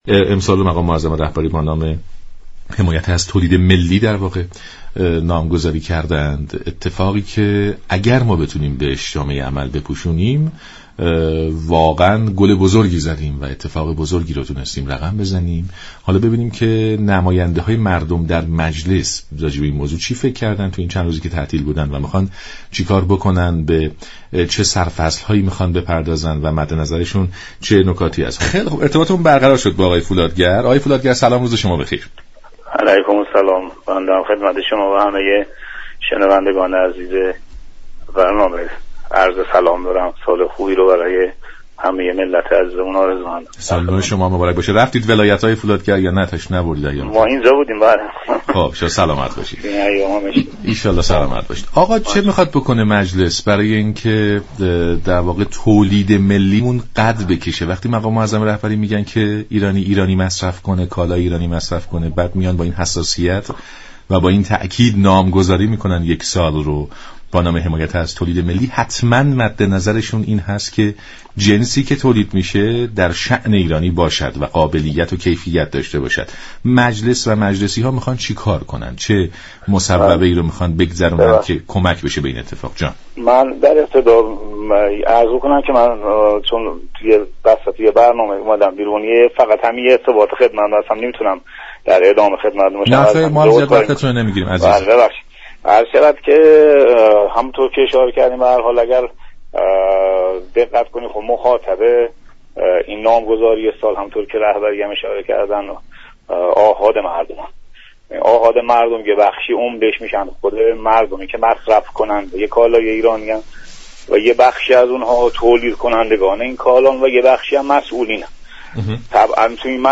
رئیس كمیسیون ویژه حمایت از تولید ملی مجلس شورای اسلامی در گفت و گو با برنامه «نمودار» گفت: مجلس شورای اسلامی به عنوان نهادی قانونگذار تلاش می كند نظارت خود بر اجرای قوانین افزایش دهد.